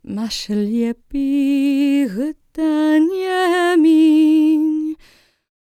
L  MOURN C03.wav